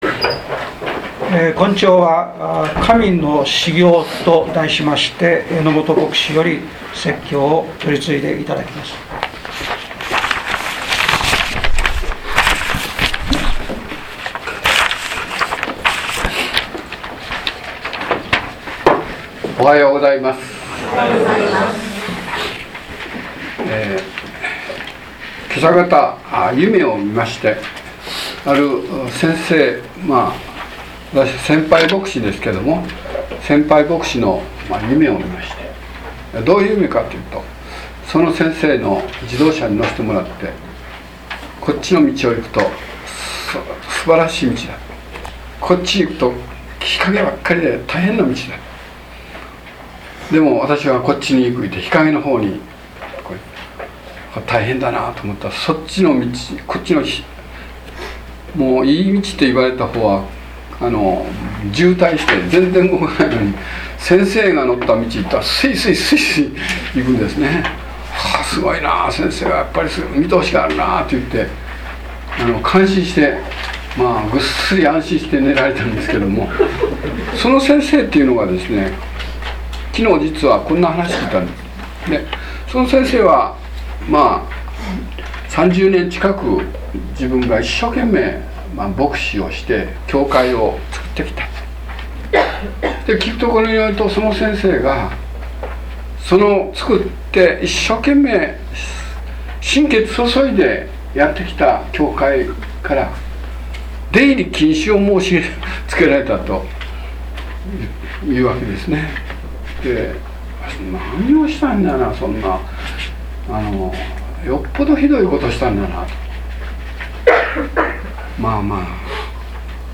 説教要旨 2013年1月20日 神の嗣業 | 日本基督教団 世光教会 京都市伏見区